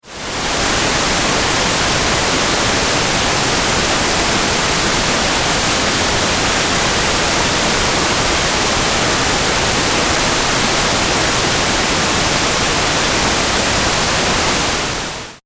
ホワイト・ノイズ
a118white.mp3